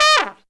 Index of /90_sSampleCDs/Zero-G - Phantom Horns/TRUMPET FX 3